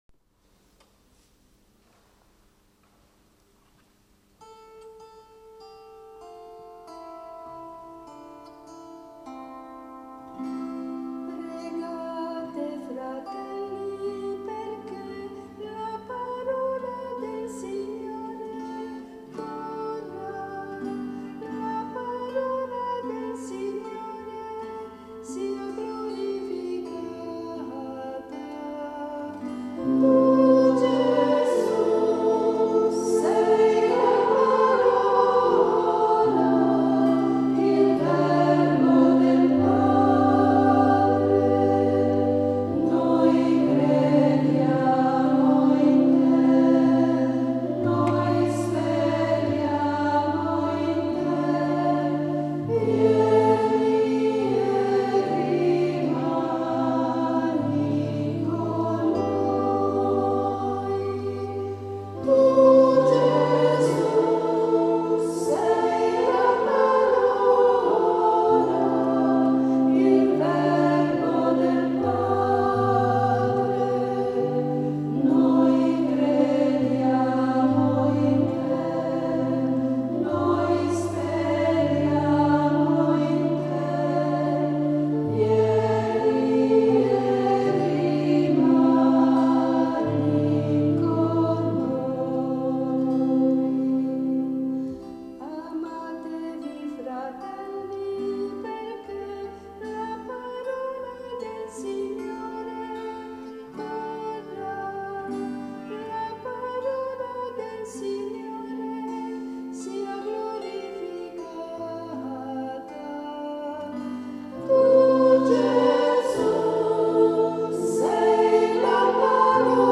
Terzo inno dell’Anno Biblico